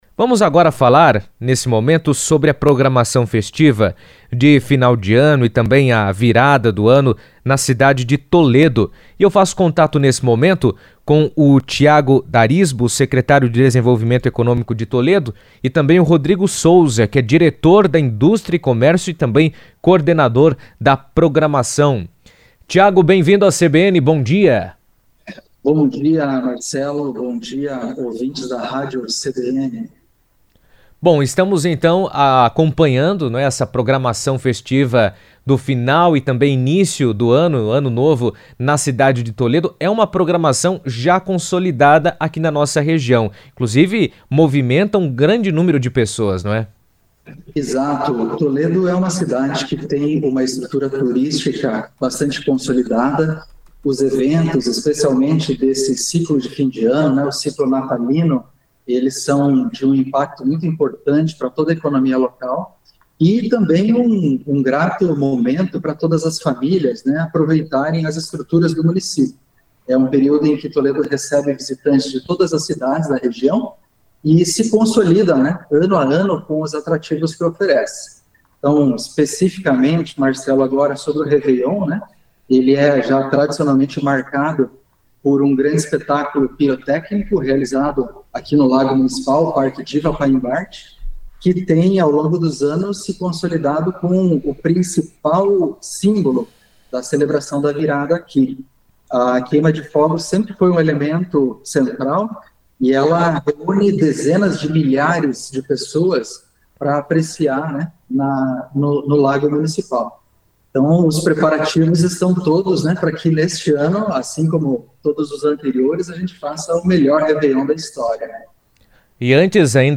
falaram sobre o assunto em entrevista à CBN, destacando os preparativos e a importância da festa para a cidade.